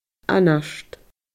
anart /anaRʃd/